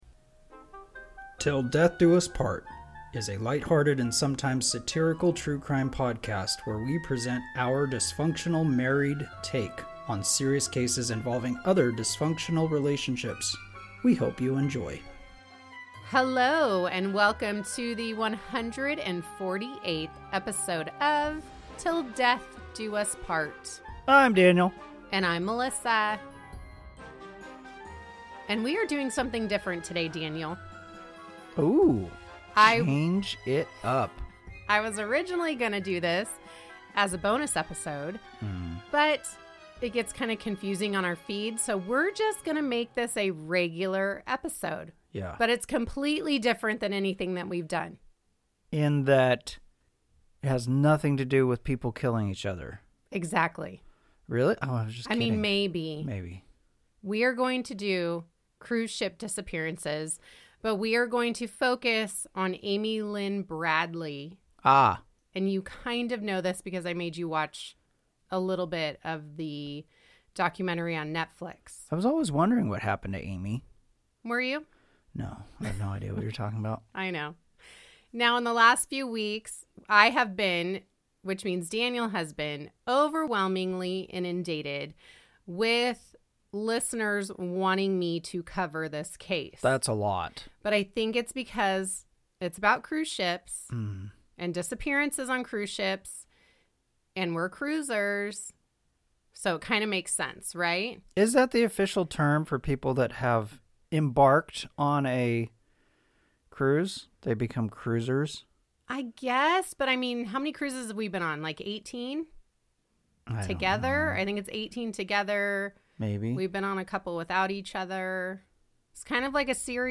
Marriage, Horror, Lifestyle, Halloween, Crime, Husband, Couple, Comedy, True Crime, Society & Culture, Satire, Murder, Wife